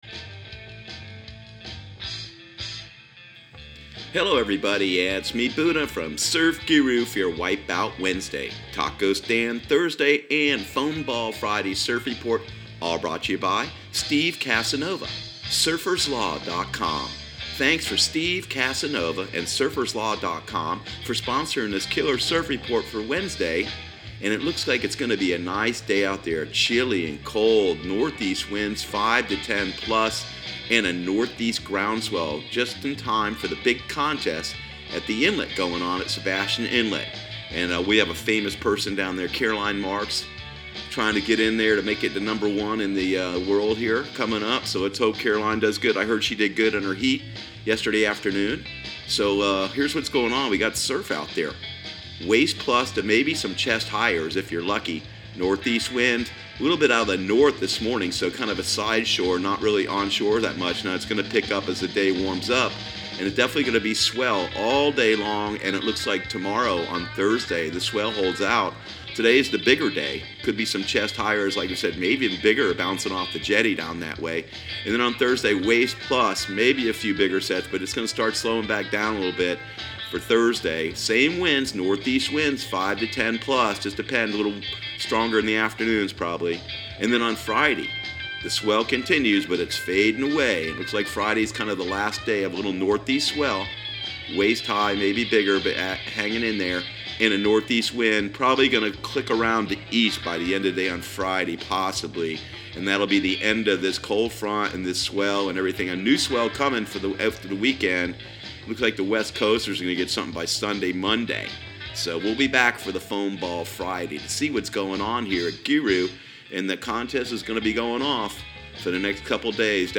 Surf Guru Surf Report and Forecast 01/16/2019 Audio surf report and surf forecast on January 16 for Central Florida and the Southeast.